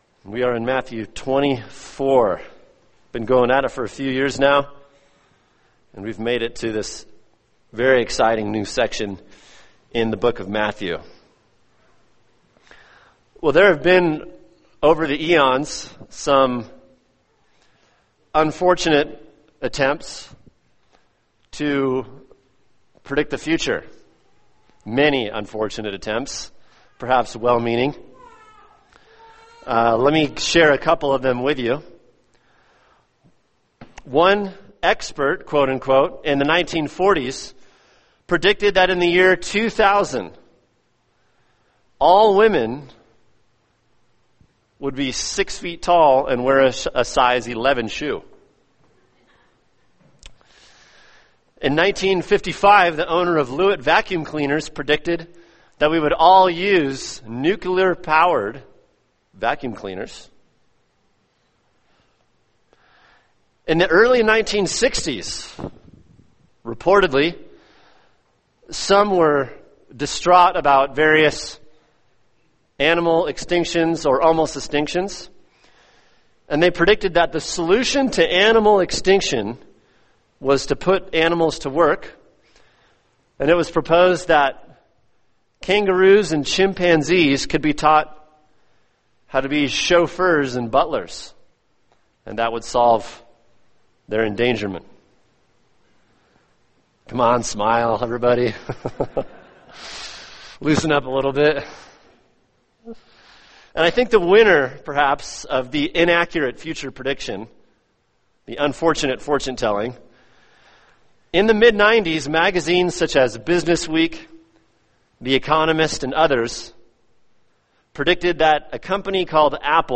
[sermon] Matthew 24:1-8 The End Times: An Introduction | Cornerstone Church - Jackson Hole